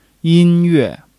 yin1--yue4.mp3